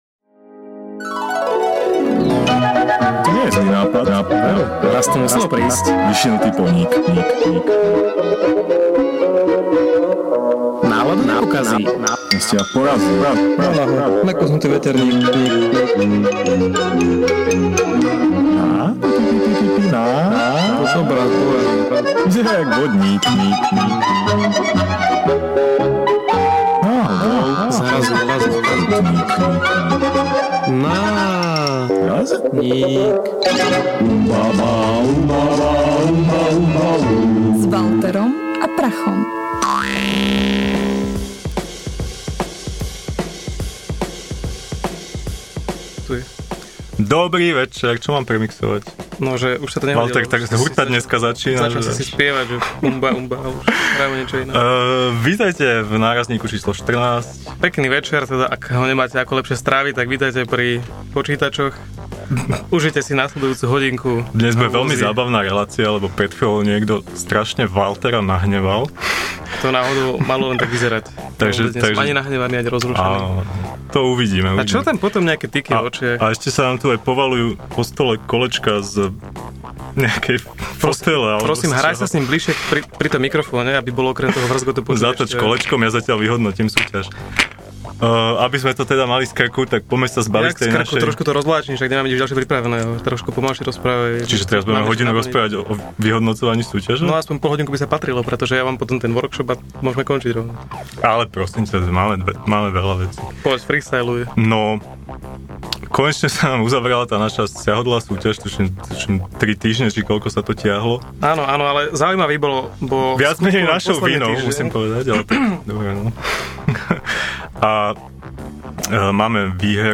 Podcasty NÁRAZNÍK / Ukecaná štvrtková relácia rádia TLIS NÁRAZNÍK #14 / Škandál 15. apríla 2012 Škandál!